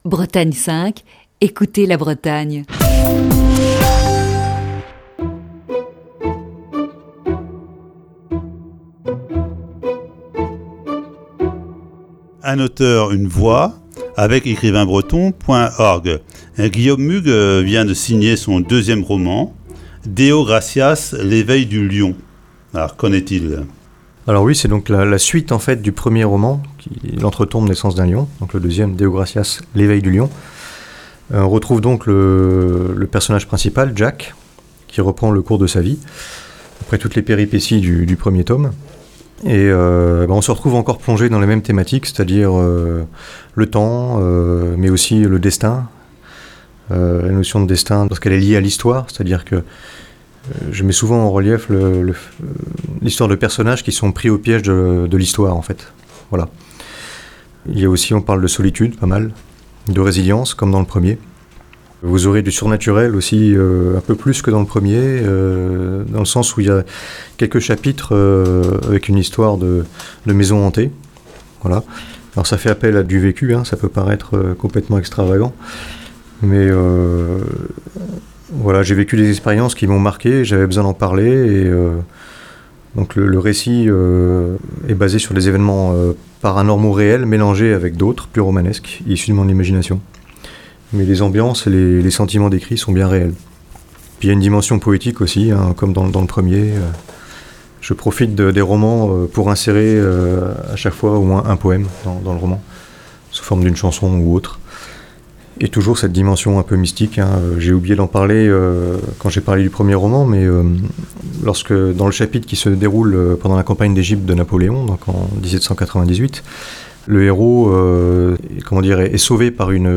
Voici ce vendredi la cinquième et dernière partie de cet entretien.